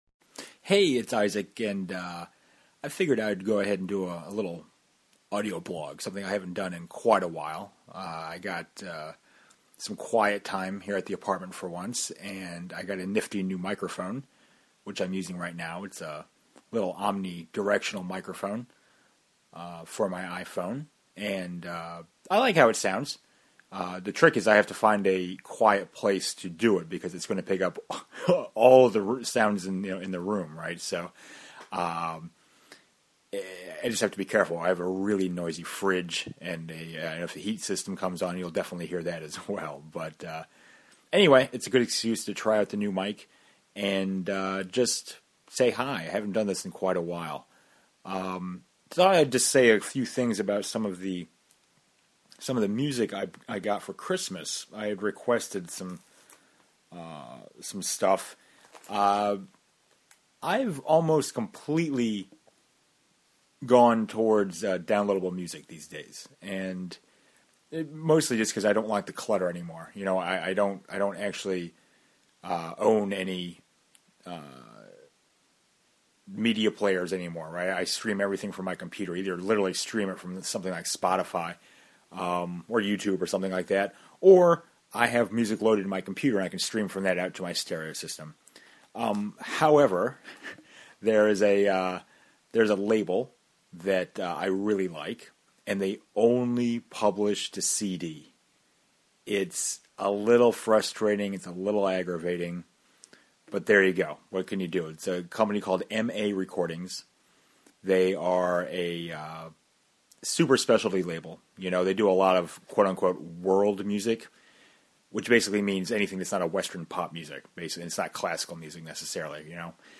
Here’s a new audio blog!